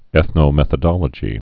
(ĕthnō-mĕthə-dŏlə-jē)